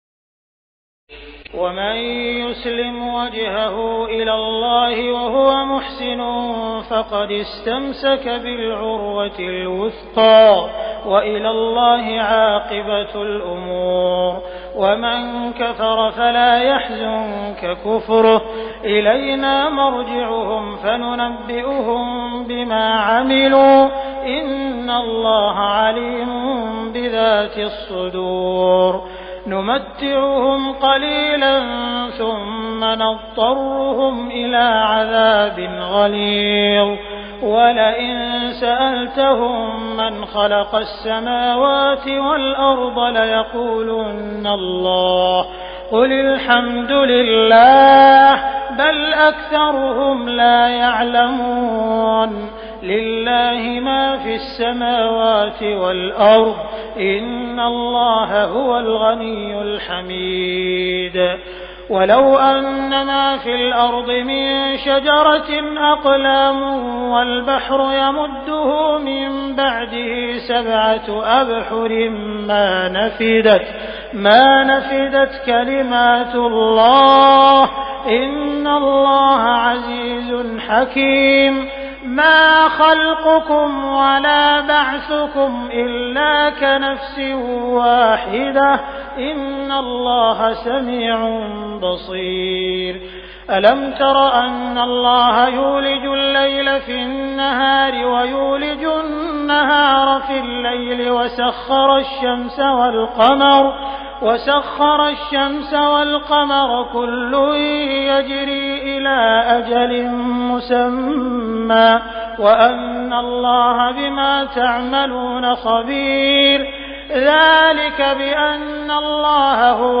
تراويح الليلة العشرون رمضان 1418هـ من سور لقمان (22-34) و السجدة و الأحزاب (1-34) Taraweeh 20 st night Ramadan 1418H from Surah Luqman and As-Sajda and Al-Ahzaab > تراويح الحرم المكي عام 1418 🕋 > التراويح - تلاوات الحرمين